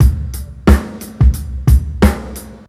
Tokyo Low 90bpm.wav